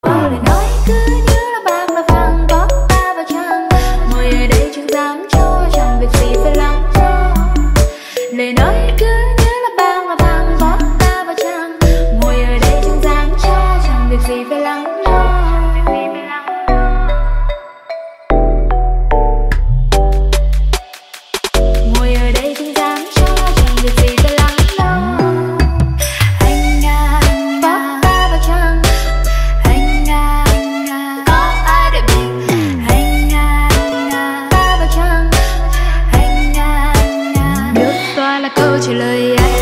Thể loại nhạc chuông: Nhạc trẻ HOT